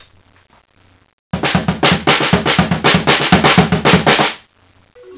drum.wav